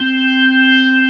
55o-org13-C4.aif